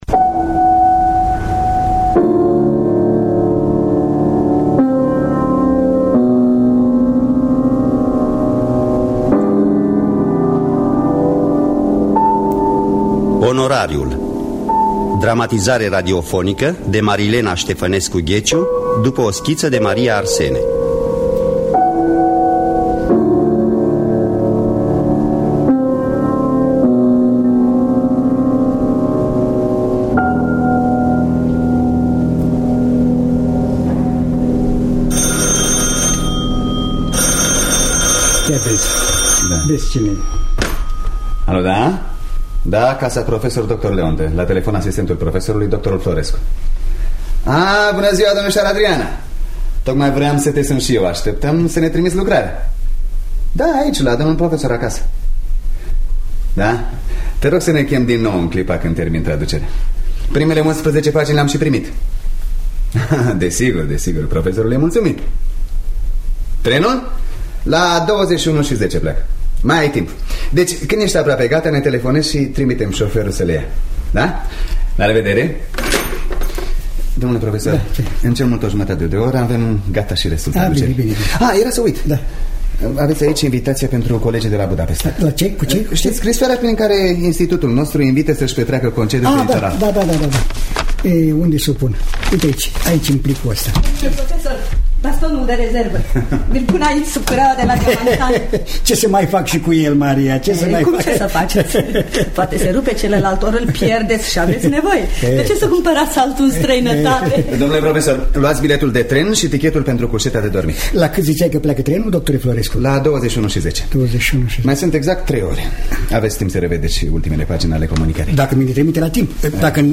Dramatizarea si adaptarea radiofonică